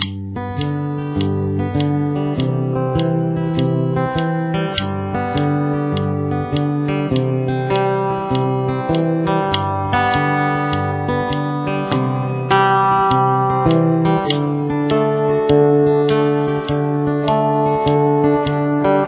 Verse Riff
Use fingerpicking to play this one.